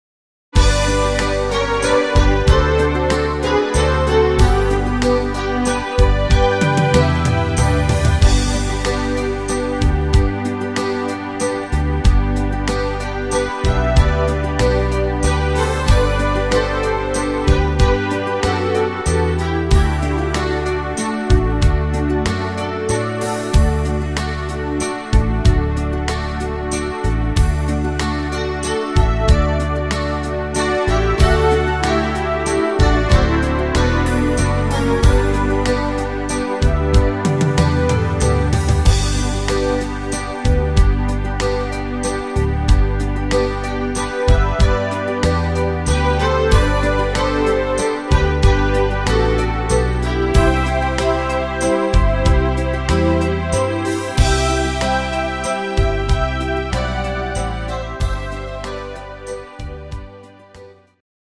instumental Klarinette